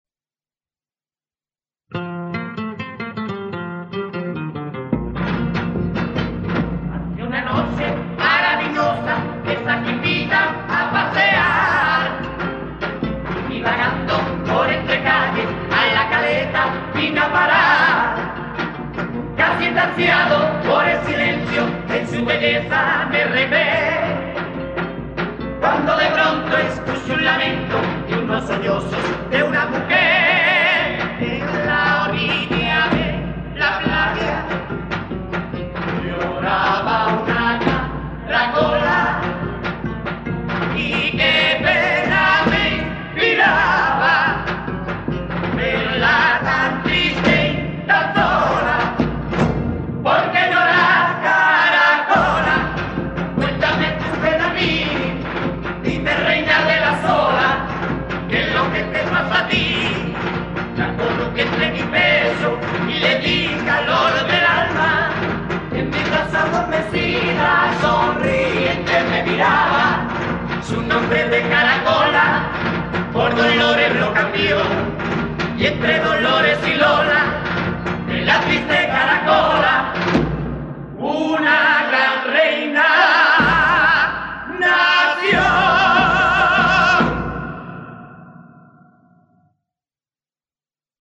Pasodoble ‘La caracola’
04.La-caracola-Pasodoble.mp3